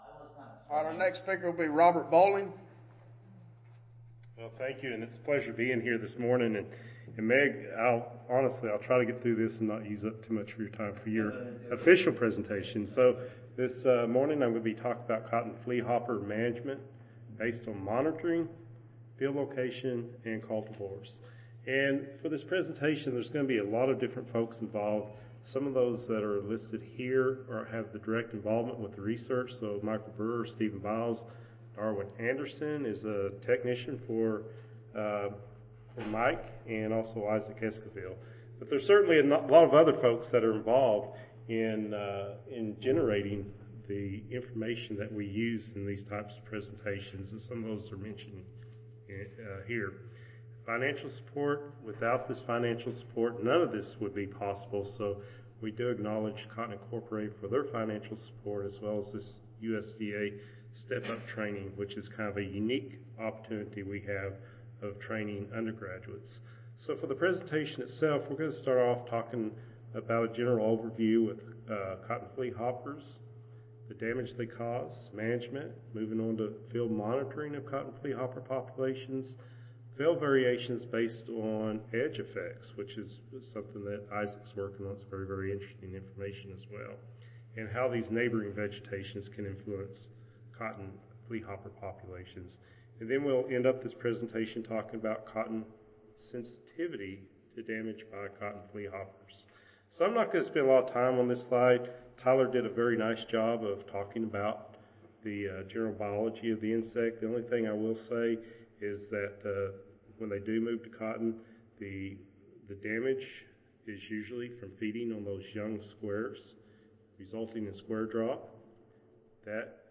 Recorded Presentation The current research investigates cultivar influence on and within-field spatial patterns of abundance of cotton fleahopper. Our results suggest a strong cultivar effect on cotton fleahopper abundance that does correspond to yield reduction.